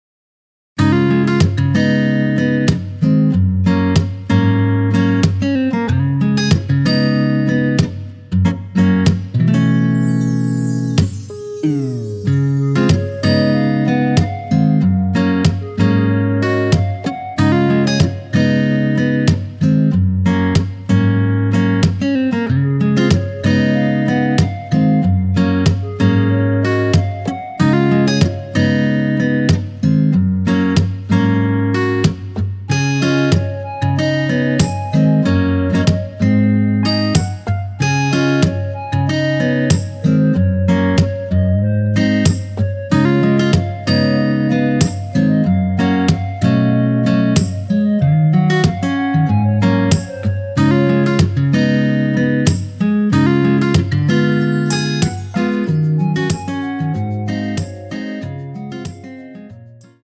원키 멜로디 포함된 MR입니다.
Db
앞부분30초, 뒷부분30초씩 편집해서 올려 드리고 있습니다.
중간에 음이 끈어지고 다시 나오는 이유는